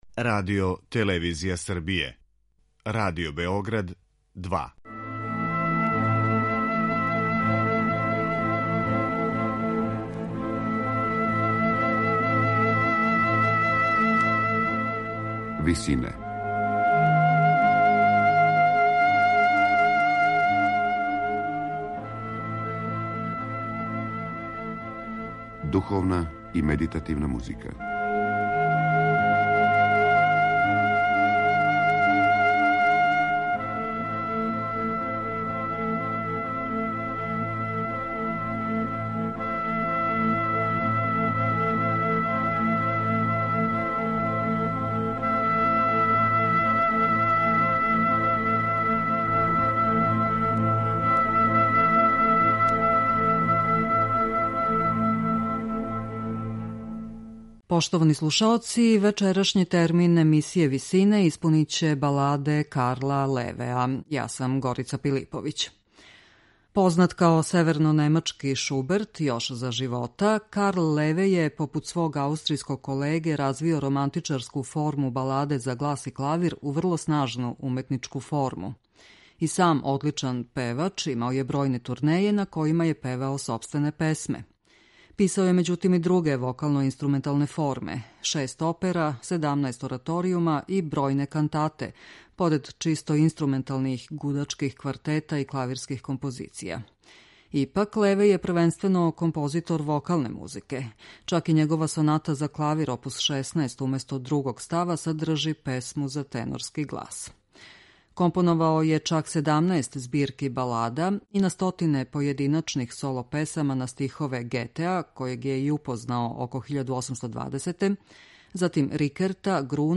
У вечерашњој емисији, његове баладе из неколико различитих опуса певаће велики тумач немачког Lied-а Дитрих Фишер Дискау.